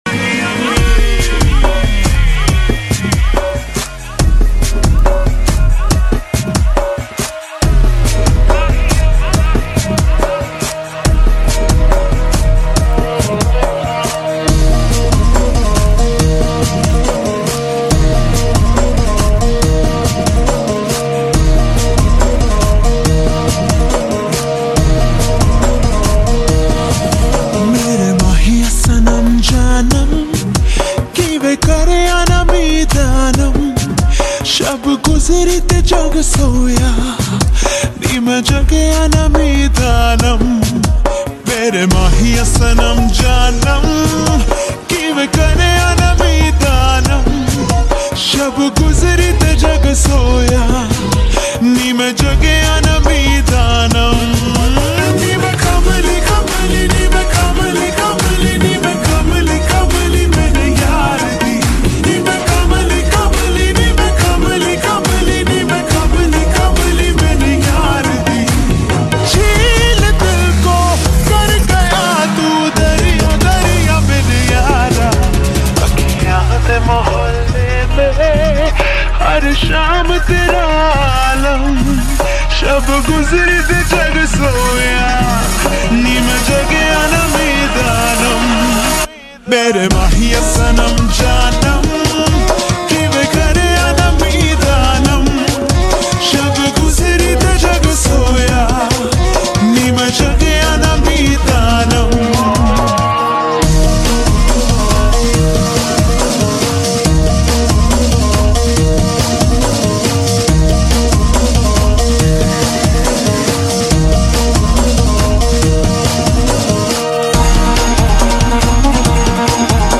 BASS BOOSTED MUSIC